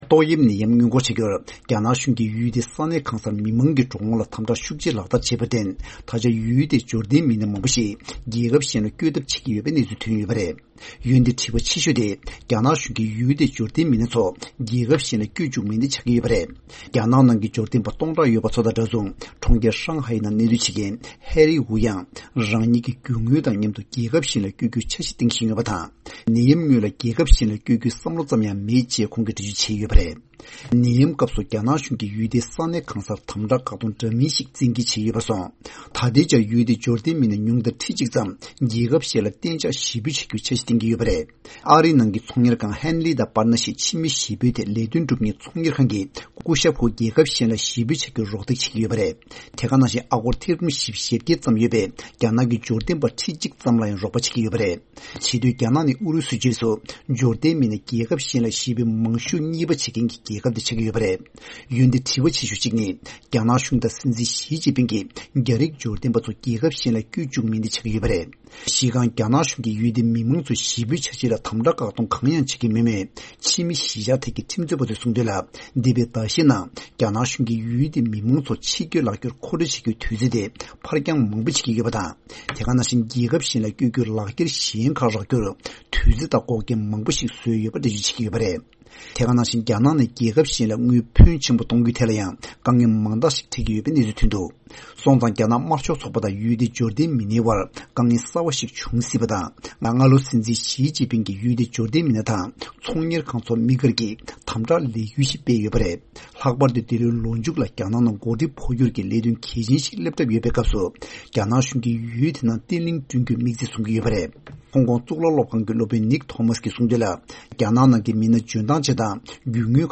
གནས་ཚུལ་སྙན་སྒྲོན་གནང་གི་རེད།